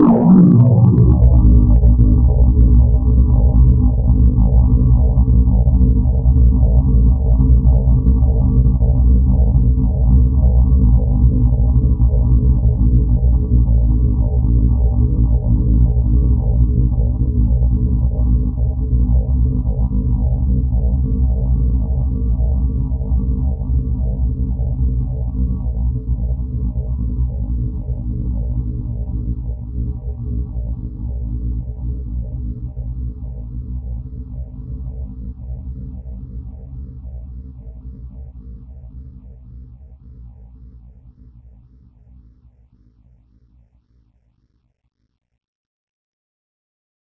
Drop_FX_7_B1.wav